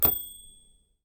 Typewriter ding_near_mono
ding keys typewriter Typewriter sound effect free sound royalty free Sound Effects